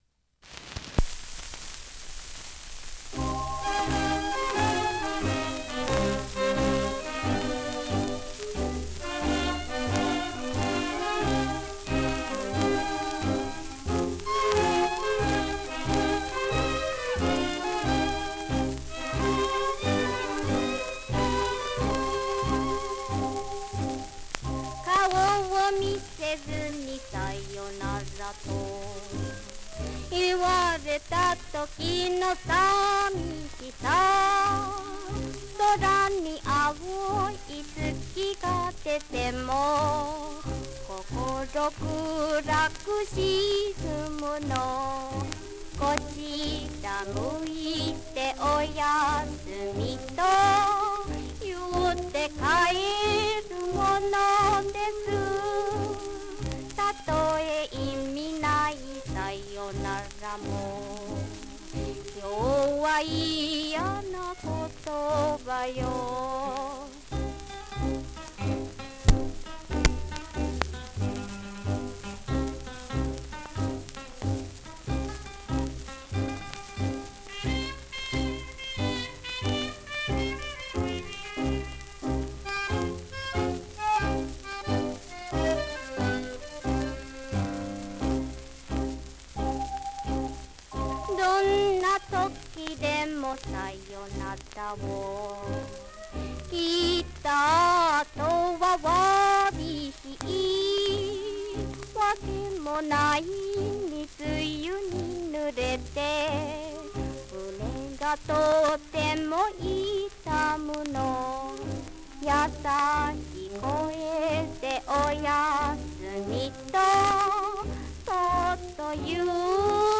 (one speaker monaural